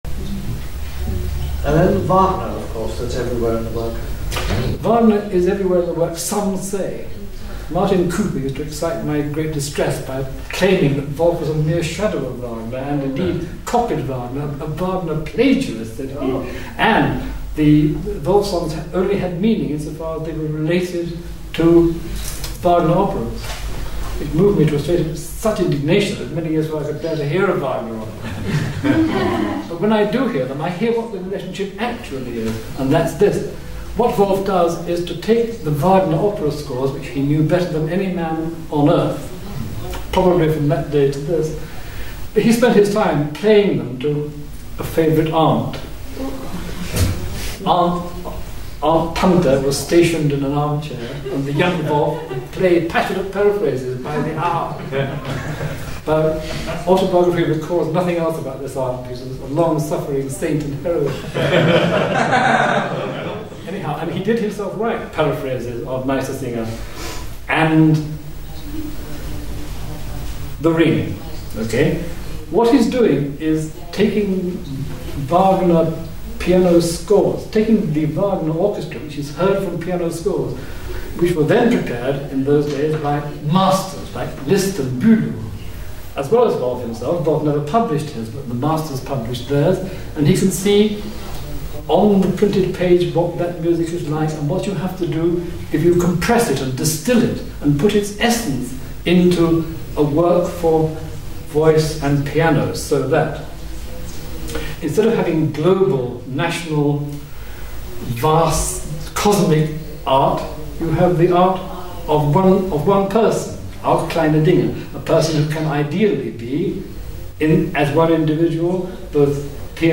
Lecture held at the William Walton Foundation, La Mortella, 12 September 1991
Please download the file: audio/mpeg audio/ogg Questions, Discussion 9.